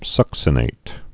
(sŭksə-nāt)